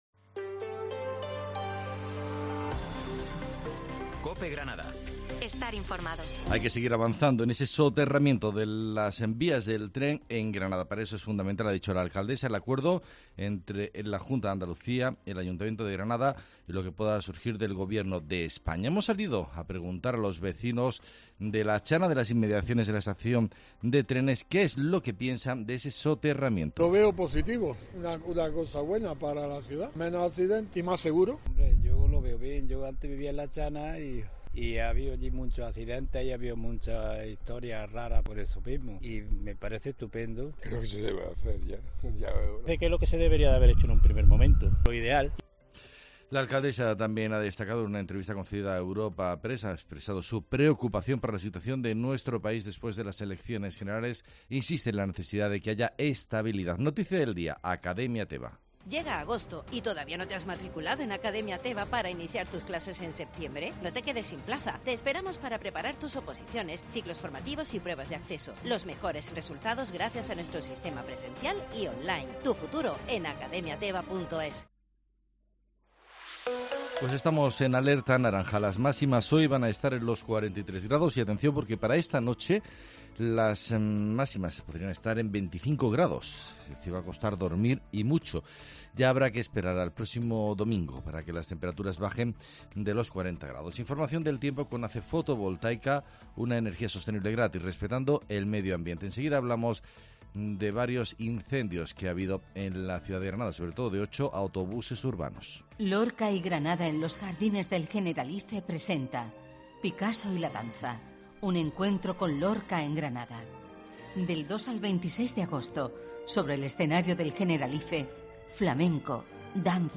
Herrera en COPE, Informativo del 8 de agosto